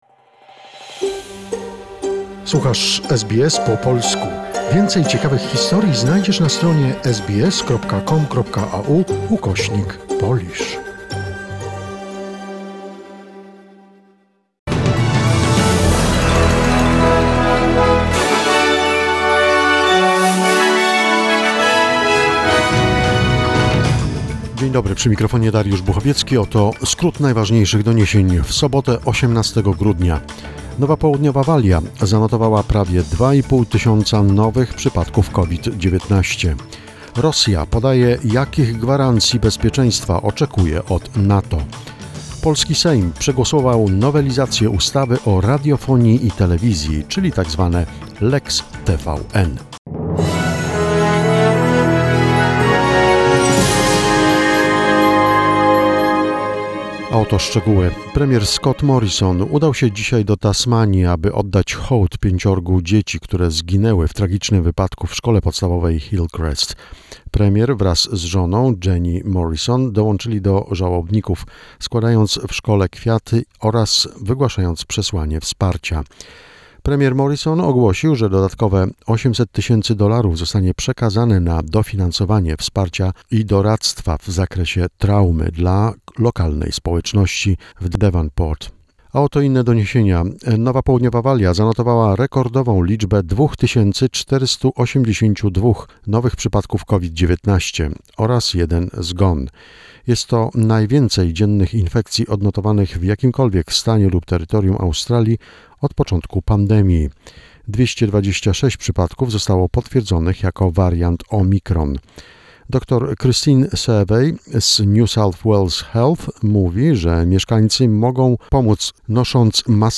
SBS News Flash in Polish, 18 December 2021